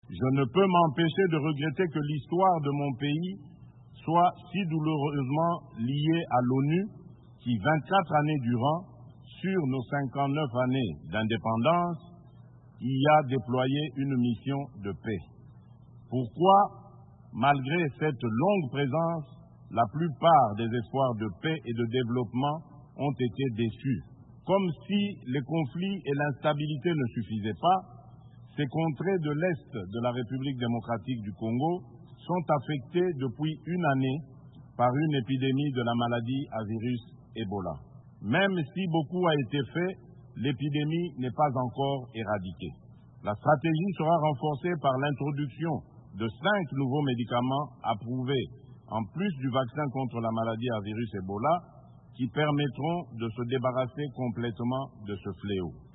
Voici un extrait de son premier discours à la tribune onusienne: